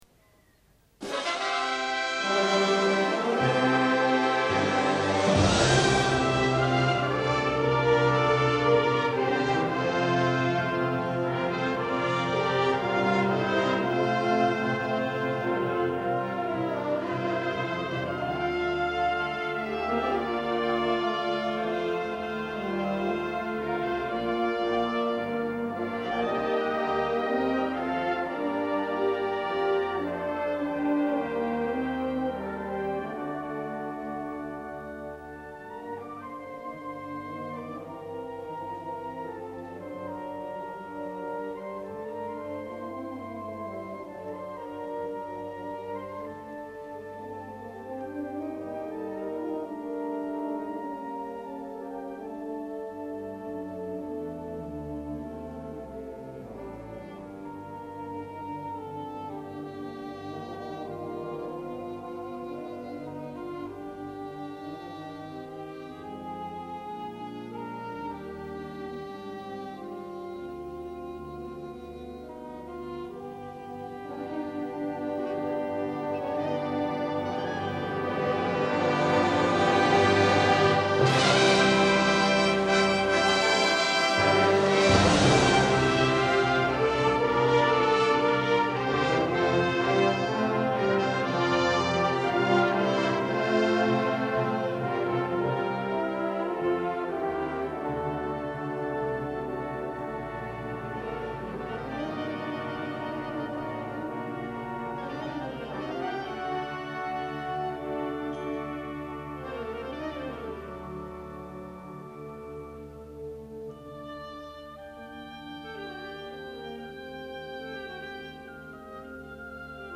第11回 全国高等学校総合文化祭
会場：名古屋市民会館